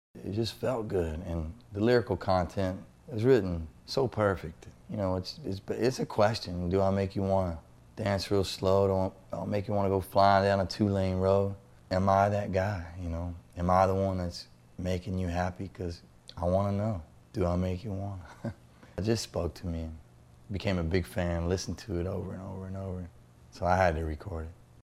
BILLY CURRINGTON TALKS ABOUT “DO I MAKE YOU WANNA,” FEATURED ON HIS ALBUM, SUMMER FOREVER.